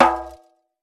PERC - KEEPER.wav